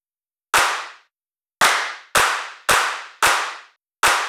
Track 15 - Hand Claps 02.wav